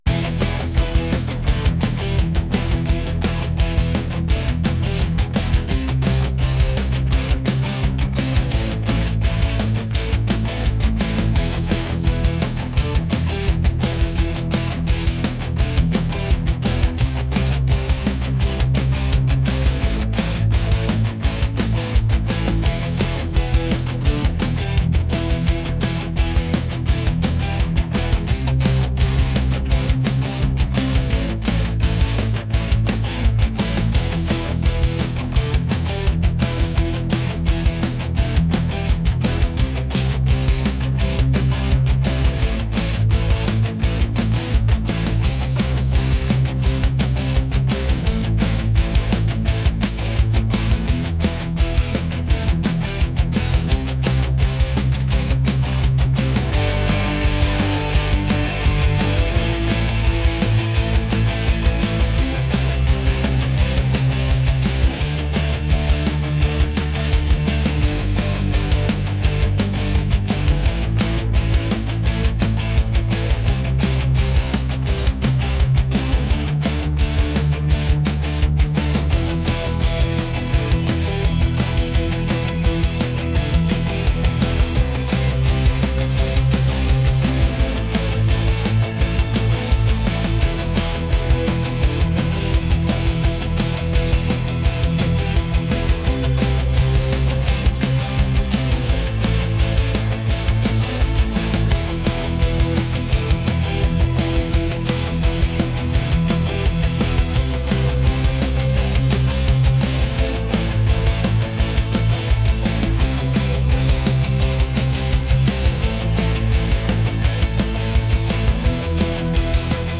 intense | energetic | rock